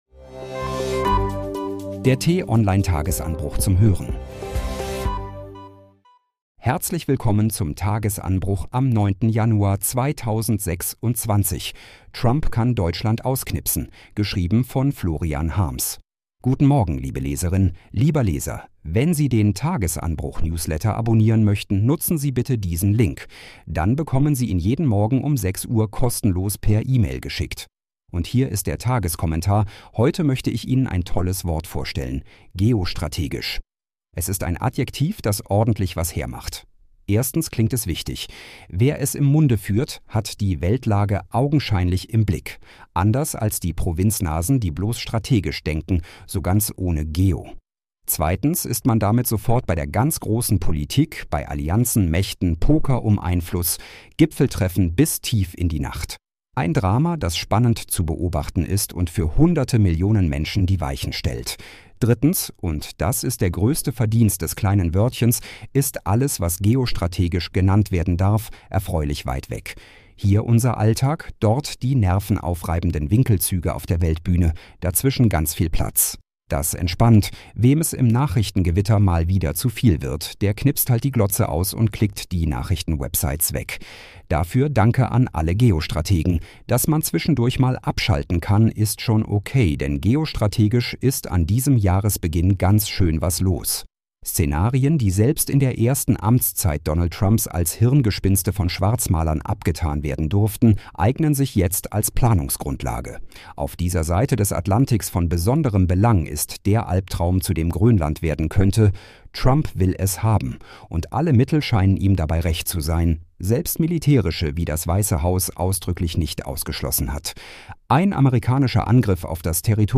zum Start in den Tag vorgelesen von einer freundlichen KI-Stimme –